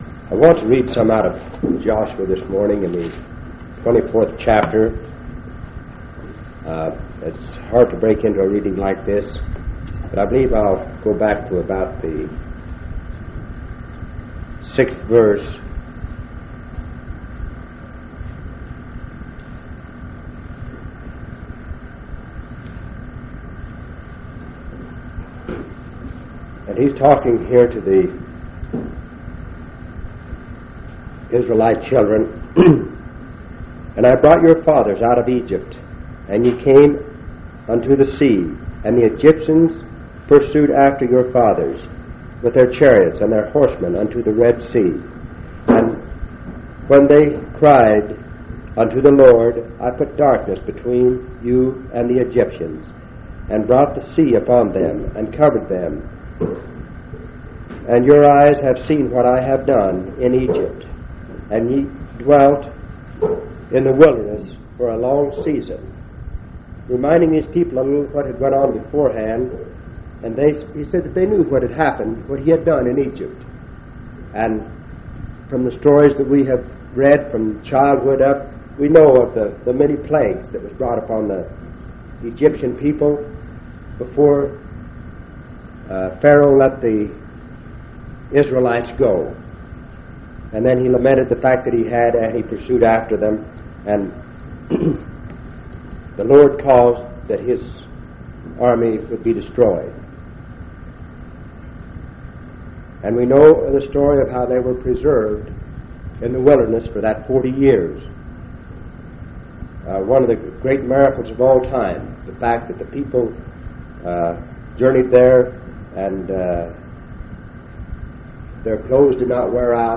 10/30/1977 Location: Grand Junction Local Event